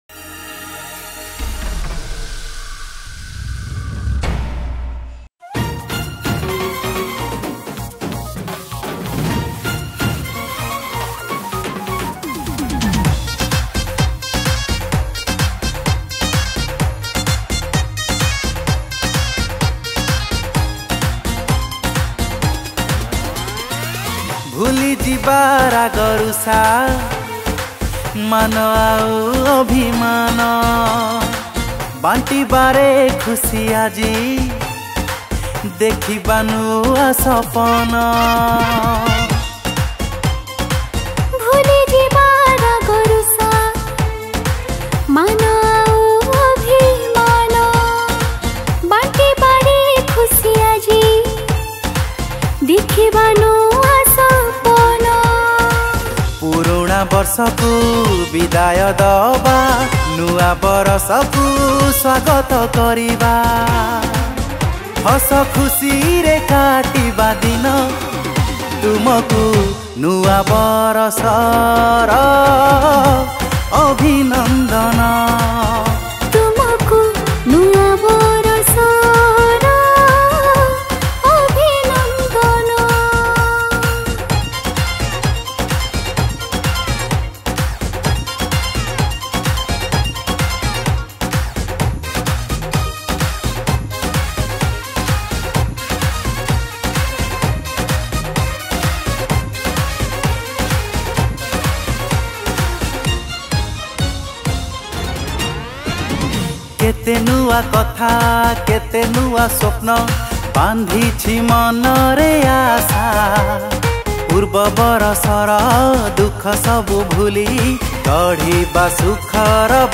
Happy New Year Song
Keyboard